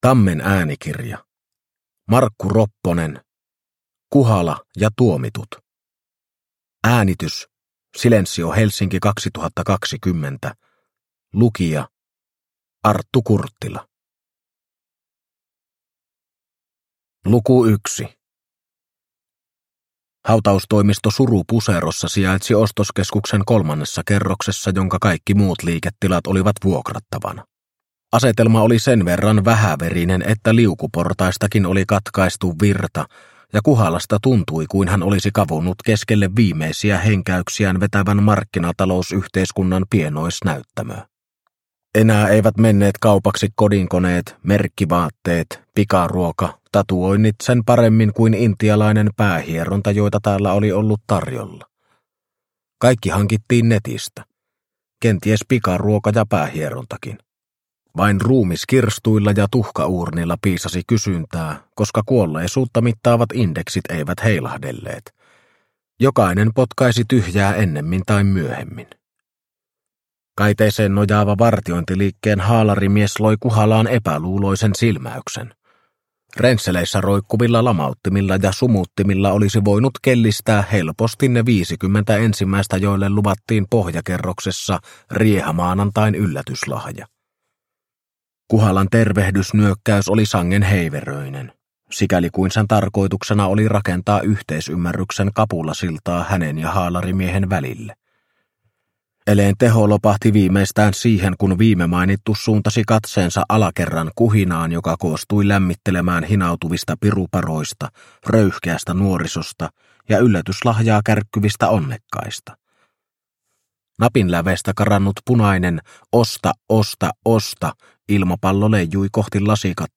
Kuhala ja tuomitut – Ljudbok – Laddas ner